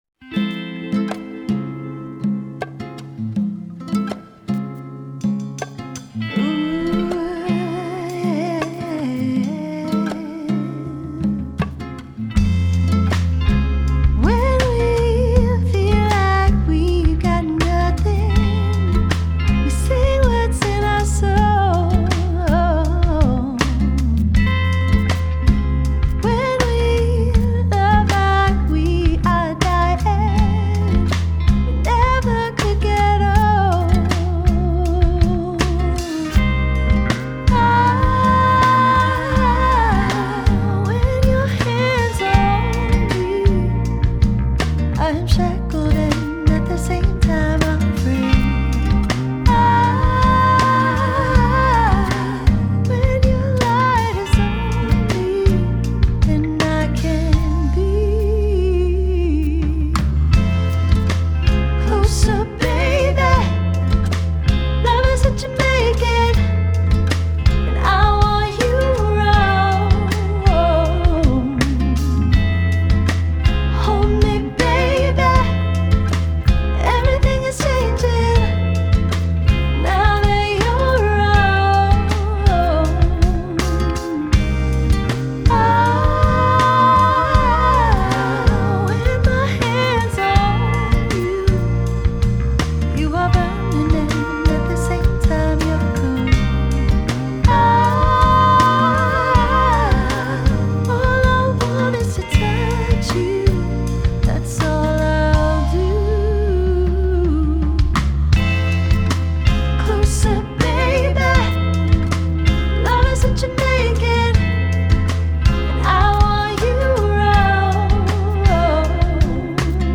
Genre: Folk Pop, Jazzy Folk